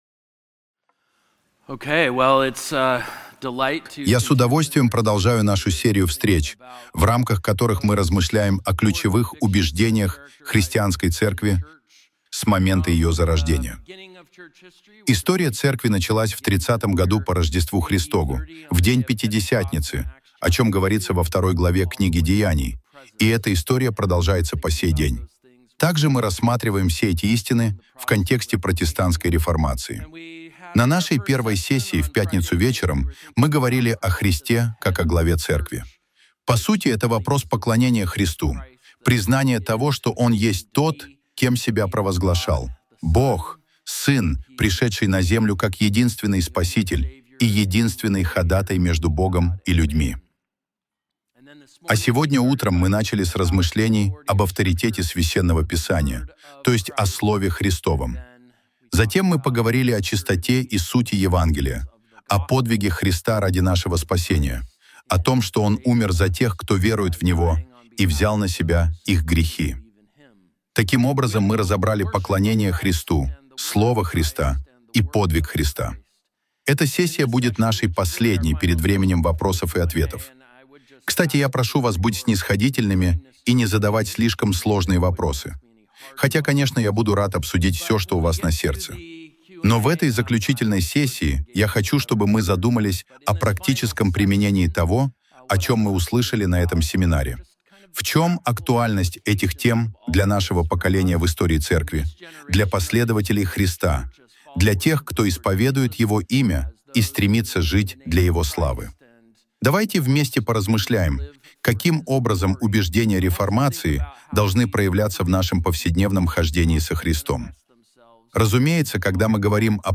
Семинар